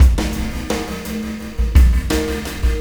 Pulsar Beat 18.wav